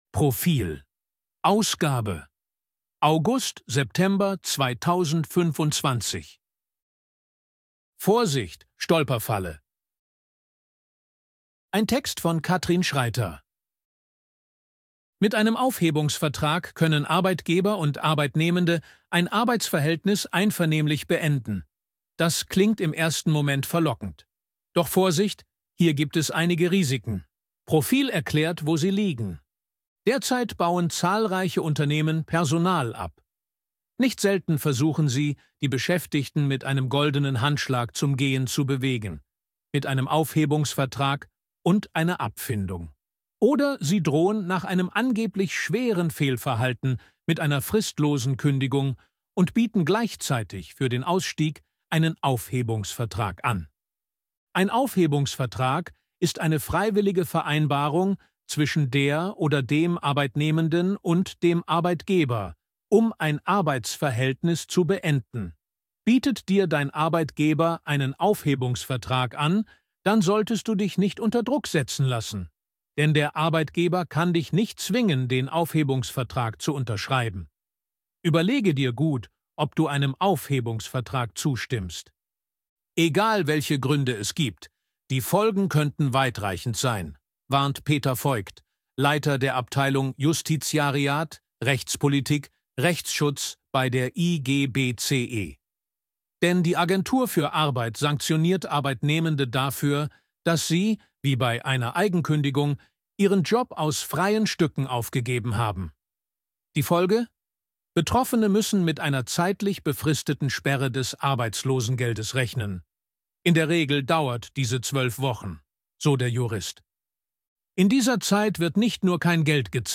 ElevenLabs_252_KI_Stimme_Mann_Service_Arbeit.ogg